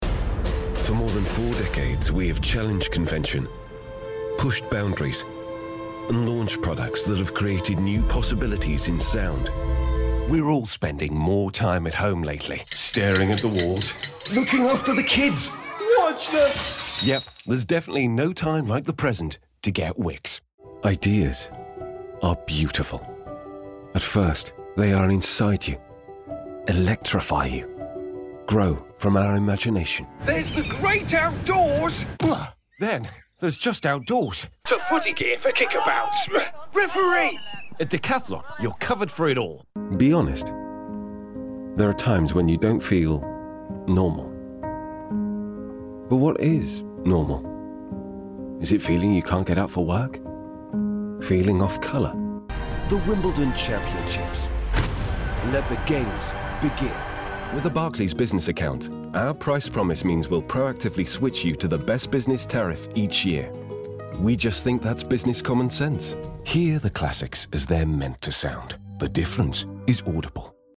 Anglais (Britannique)
Profonde, Naturelle, Mature, Chaude, Corporative
Commercial
Clear, professional and persuasive, it means hecan deliver your story with the utmost assurance and impact.